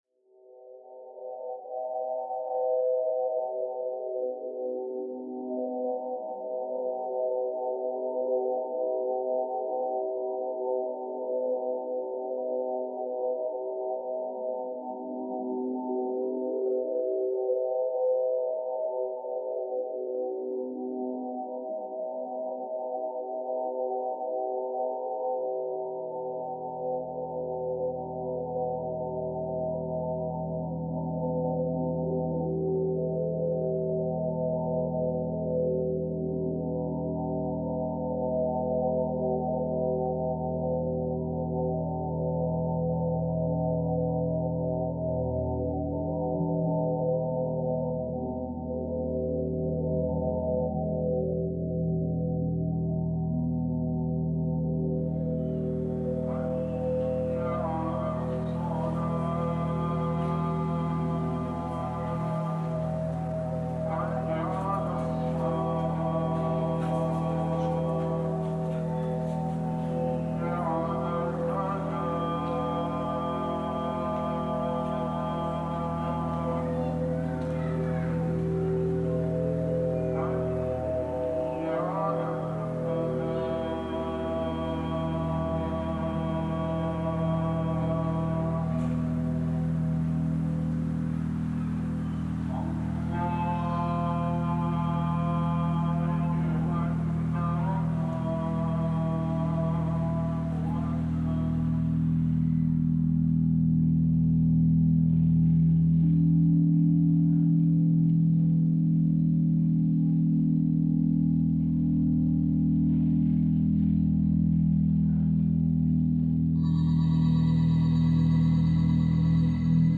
Tirana prayer sounds reimagined